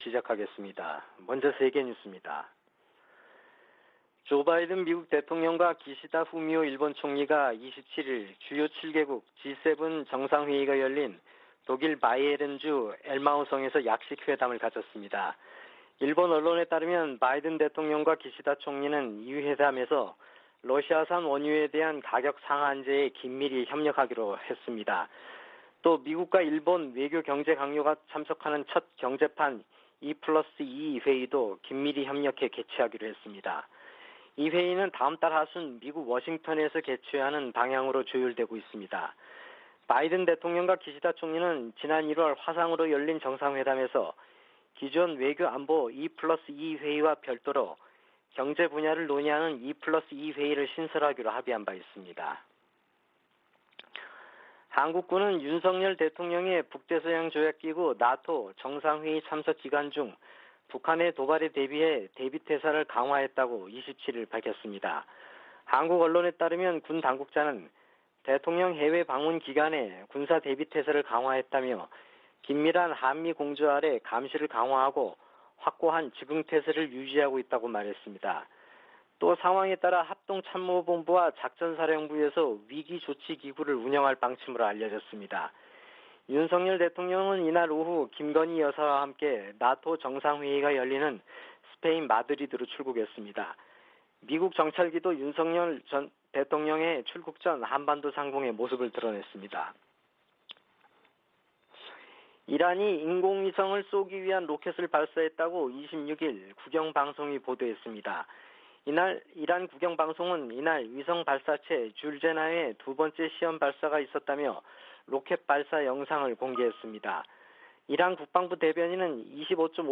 VOA 한국어 '출발 뉴스 쇼', 2022년 6월 28일 방송입니다. 권영세 한국 통일부 장관은 북한이 7차 핵실험을 할 경우 엄청난 비판에 직면할 것이라고 경고했습니다. 미국 의회 산하 위원회가 한국의 난민정책에 관한 청문회에서 문재인 정부에 의한 탈북 어민 강제북송을 비판했습니다. 알래스카 미군 기지가 북한의 미사일 위협을 24시간 감시하고 있다고 강조했습니다.